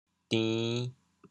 反切 端圆 调: 池 国际音标 [tĩ]